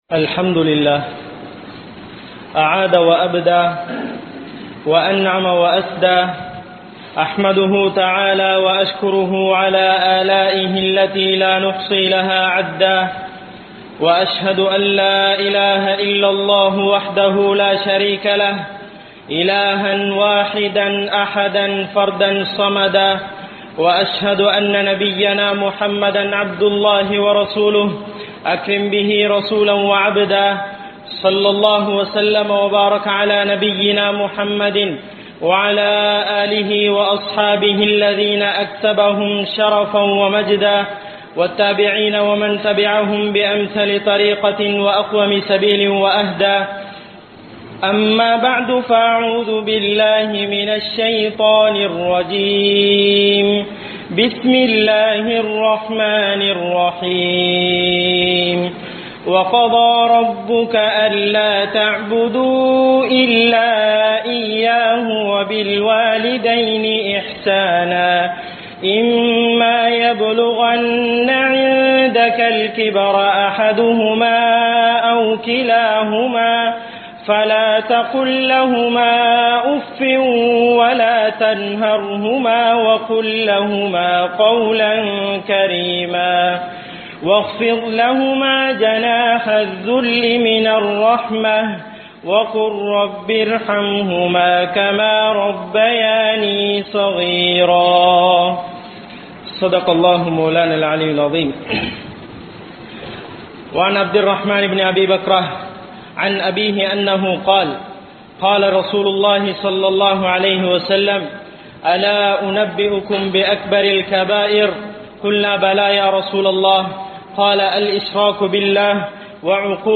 Pettroarukku Panividai Seivoam (பெற்றோருக்கு பணிவிடை செய்வோம்) | Audio Bayans | All Ceylon Muslim Youth Community | Addalaichenai